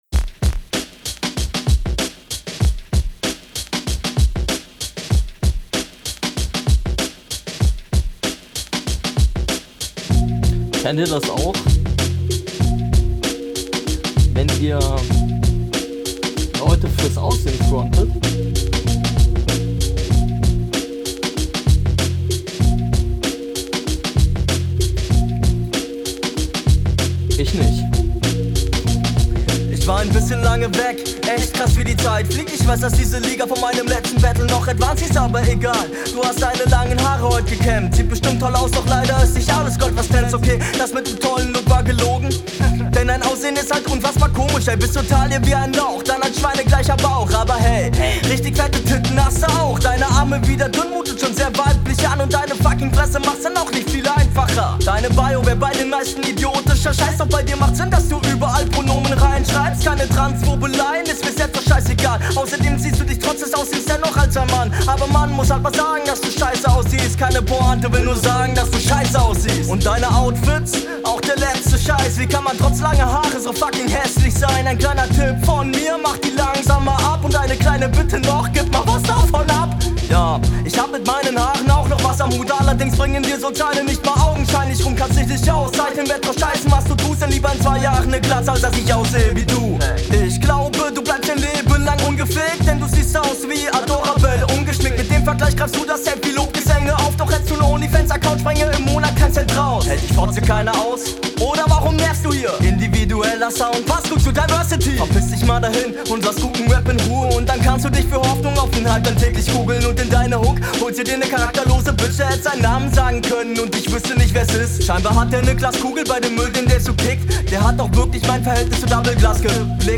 Hier wieder sehr cool gerappt, man merkt bei ihm, dass er die Beats bewusst pickt.